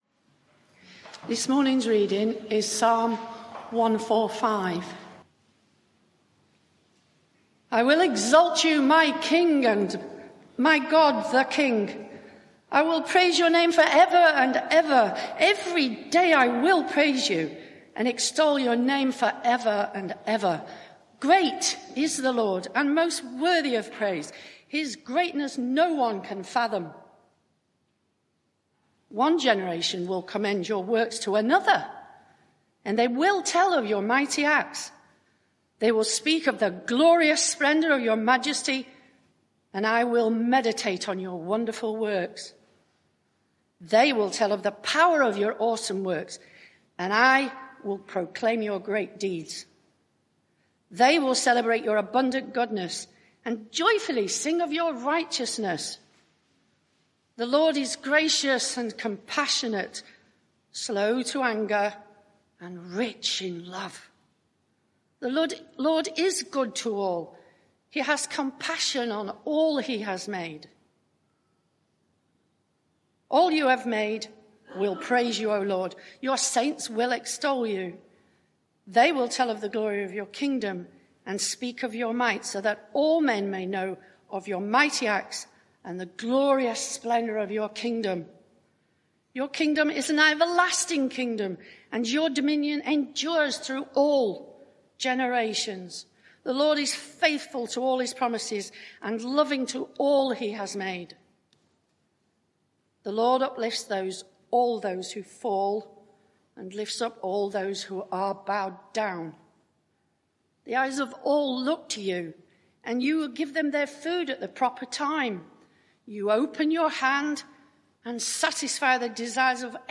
Media for 11am Service on Sun 27th Aug 2023 11:00 Speaker
Psalm 145 Series: Lord Teach us to Pray Theme: Psalm 145 Sermon (audio)